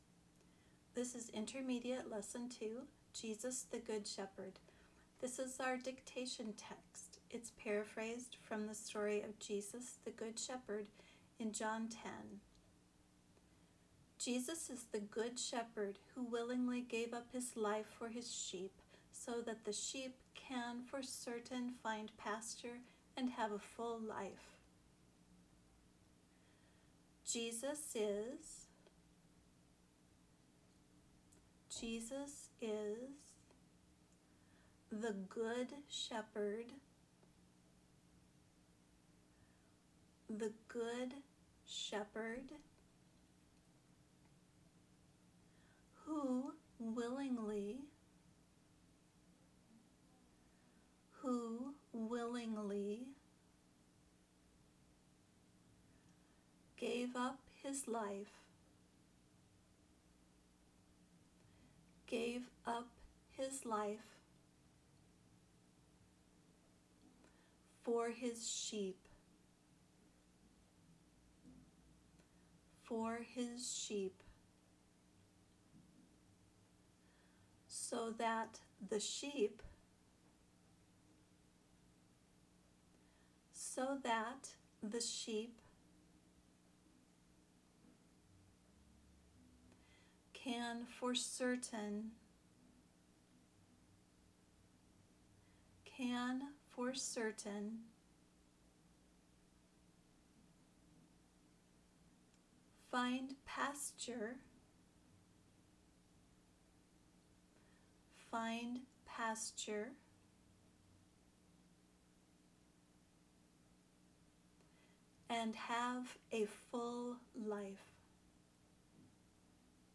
Try to imitate the speaker’s intonation, the rhythm of her speech, and the stresses she puts on words and syllables.